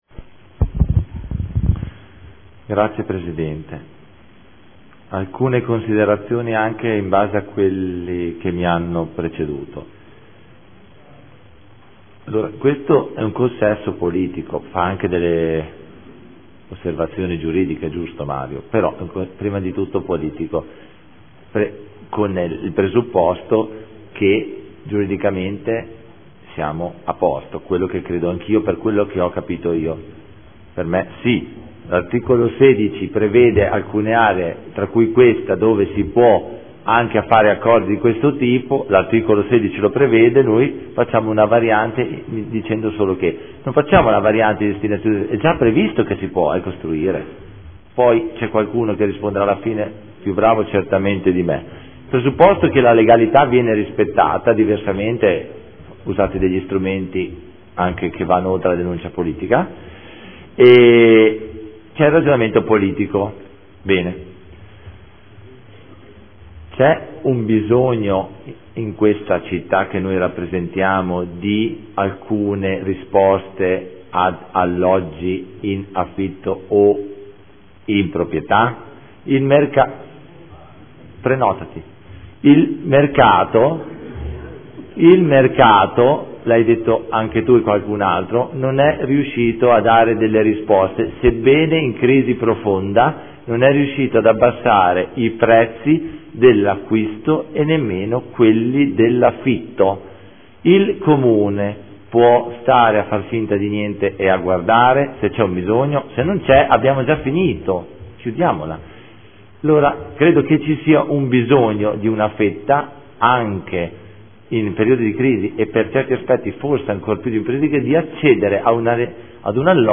Antonio Carpentieri — Sito Audio Consiglio Comunale
Seduta del 13/11/2014 Dibattito.